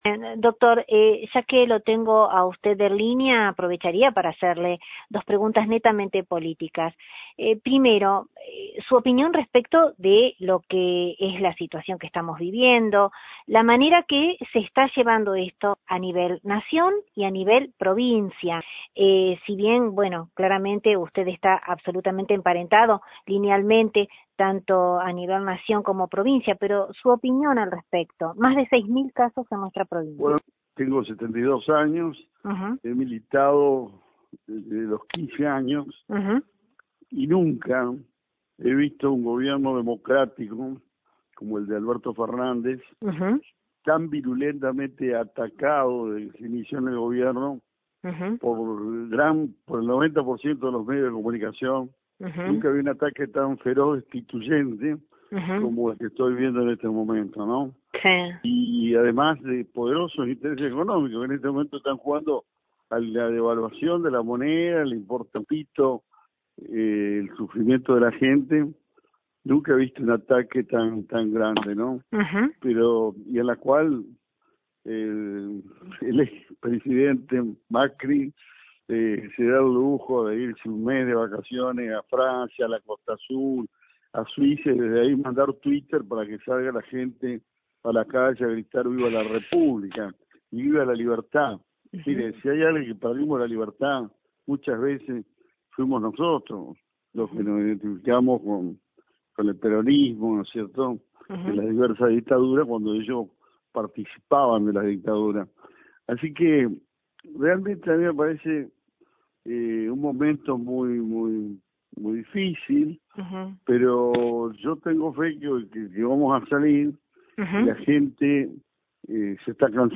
Desde LT39 NOTICIAS, dialogamos con el ex gobernador concordiense; con quien pudimos traer al presente, la figura relevante del ex caudillo Pancho Ramírez, siembra fiel de grandes ideas instaladas y cosechadas a lo largo de nuestro transitar entrerriano; como así también, obtuvimos su apreciación de un presente complicado, que impera en nuestra realidad; donde no hizo mella al hacer un análisis político, atrevesado por nombres como el de Mauricio Macri y Eduardo Duhalde.